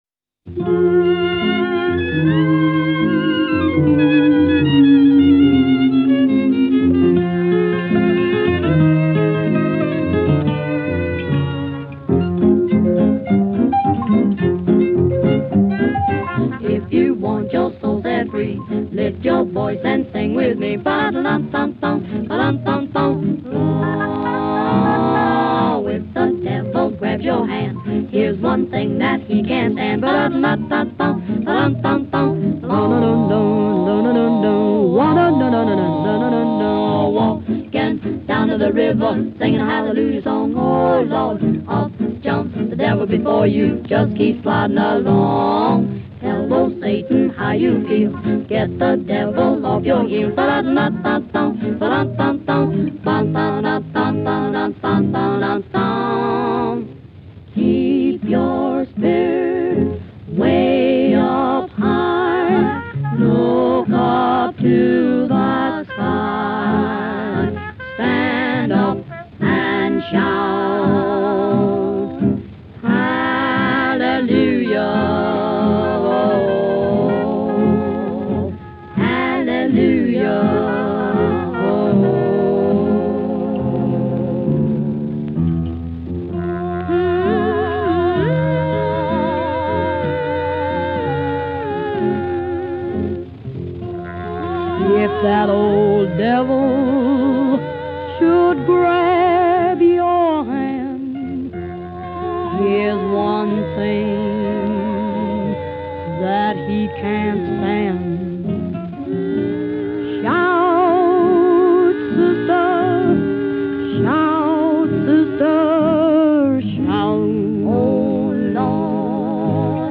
truly a pocket symphony.